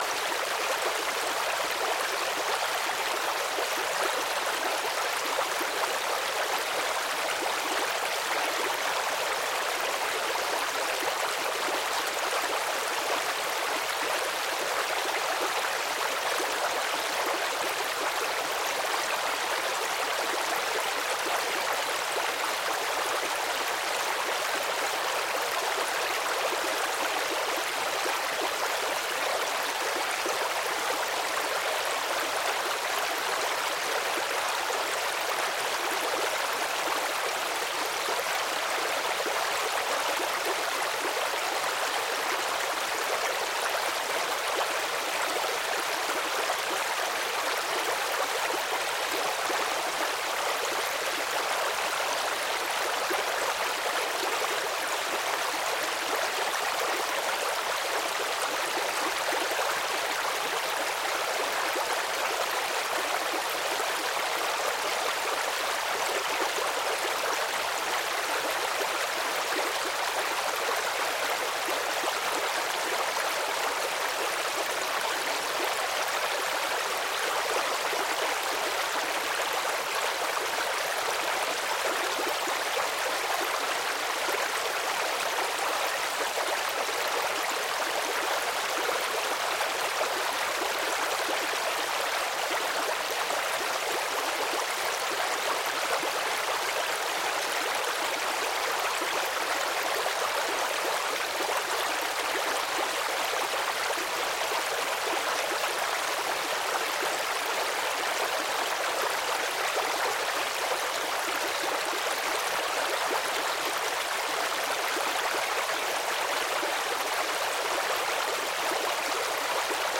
Звуки журчания ручья
Атмосферный звук записанный у ручья